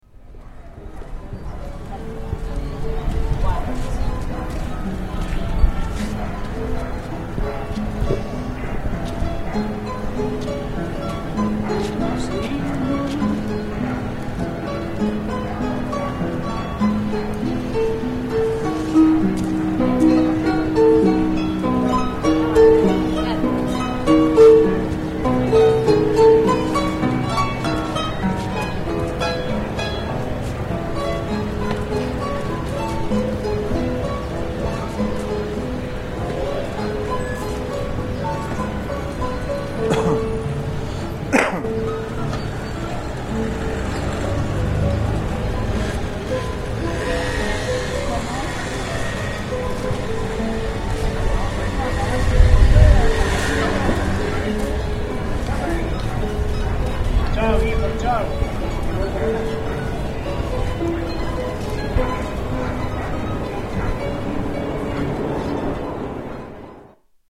Soundscape of Plaza Francia